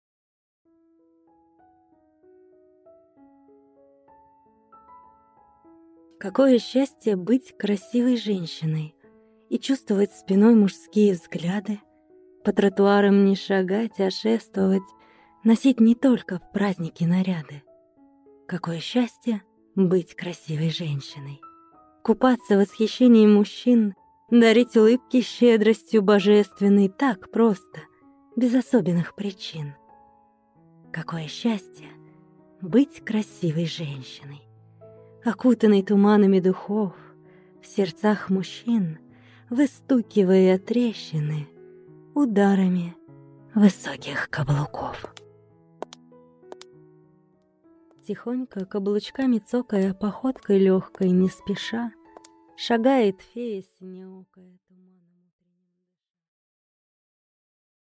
Аудиокнига Люблю…